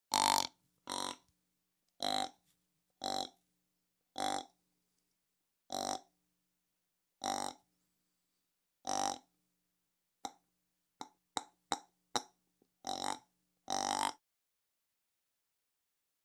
Il produit les sons authentiques du mâle, de la femelle et même du faon.
Mâle